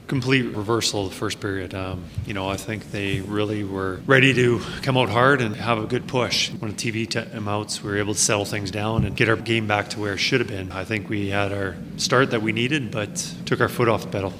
Head Coach for the Oilers Kris Knoblauch spoke to media following the loss and discussed the momentum shift in the 2nd period.